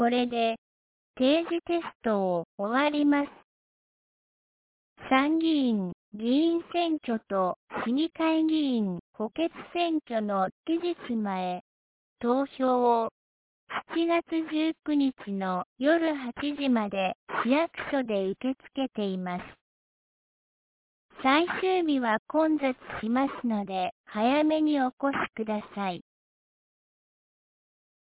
2025年07月14日 12時01分に、南国市より放送がありました。